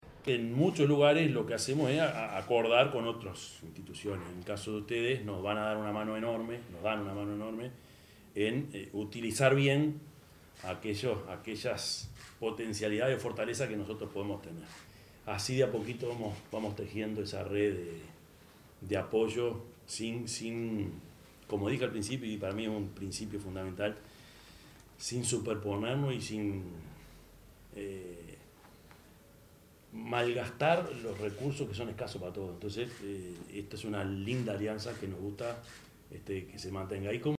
yamandu_orsi_intendente_de_canelones_3.mp3